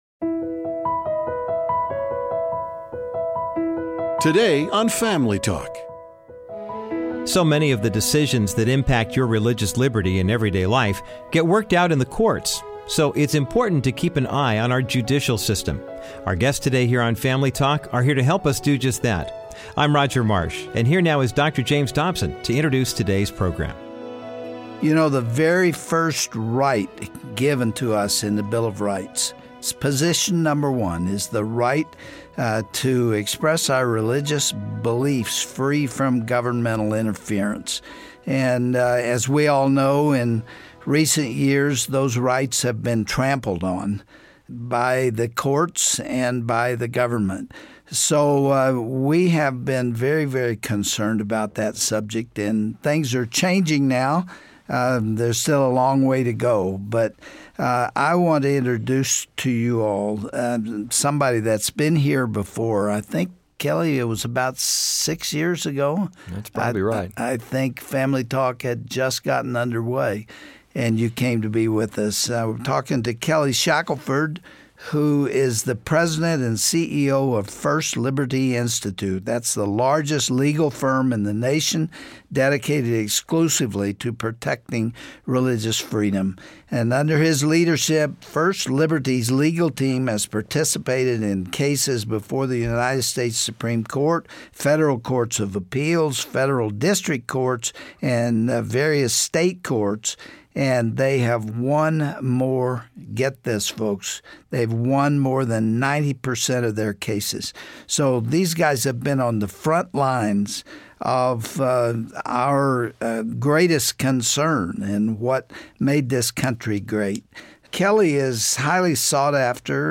Dont miss their enlightening conversation today on Family Talk with Dr. James Dobson.